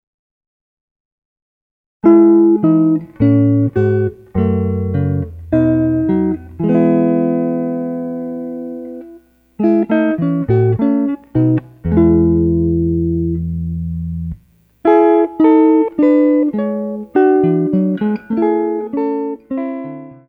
solo electric guitar